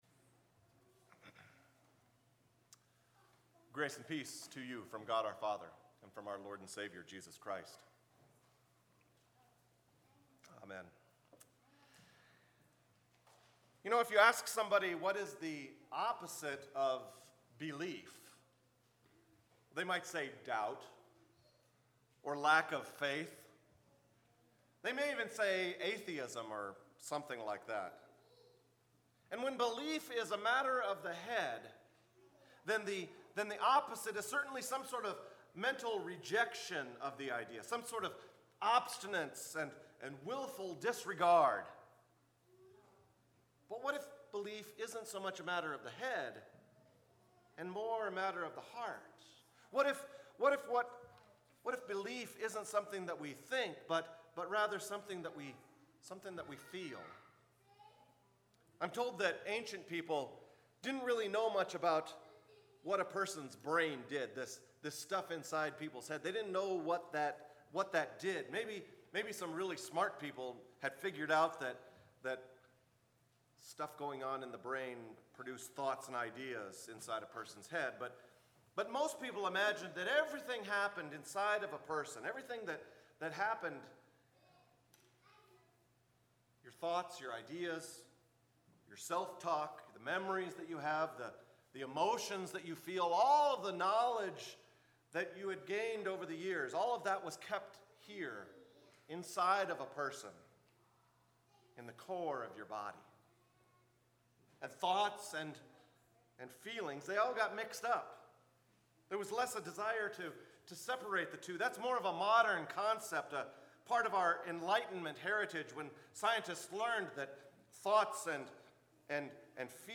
Sermons | Bethlehem Lutheran Church